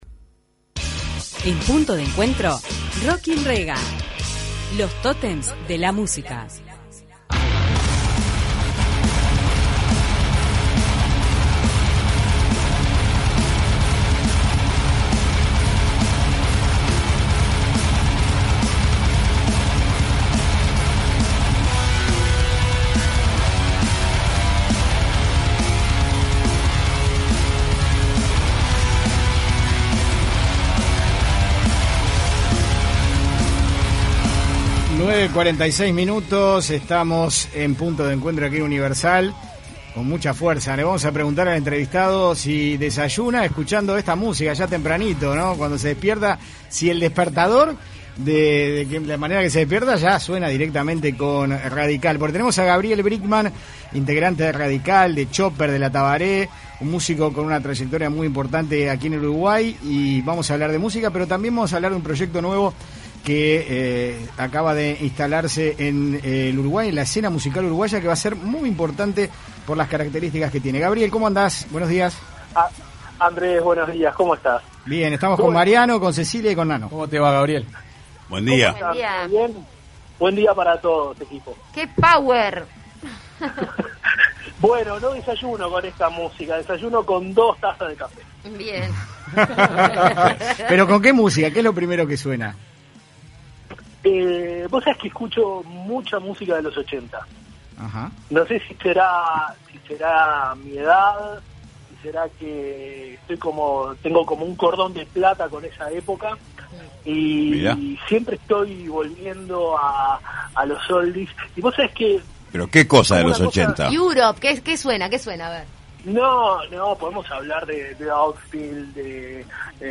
Escuche la entrevista de Punto de Encuentro